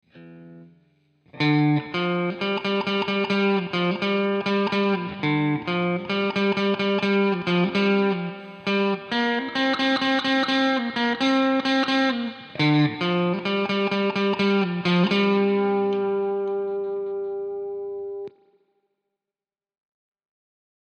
Einmal Gitarre clean und einmal Gitarre clean, mit dem eingebauten AIR Effekt.
scarlett-guitar-2.mp3